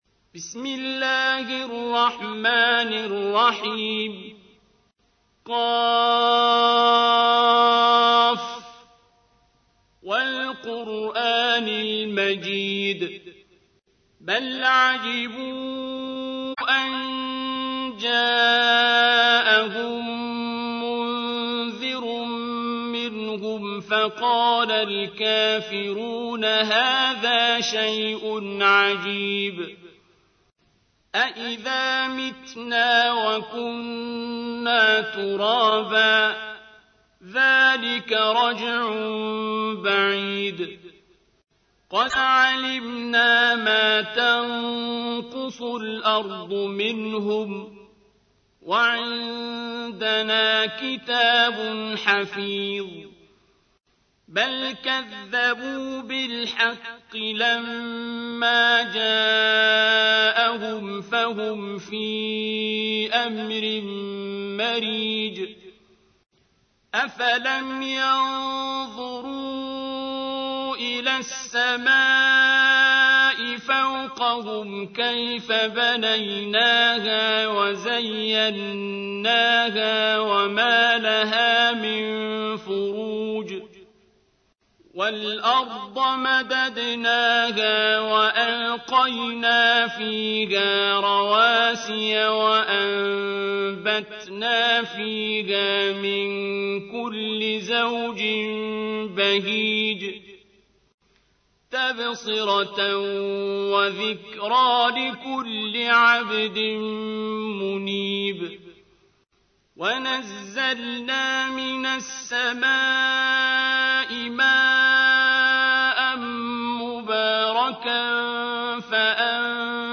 تحميل : 50. سورة ق / القارئ عبد الباسط عبد الصمد / القرآن الكريم / موقع يا حسين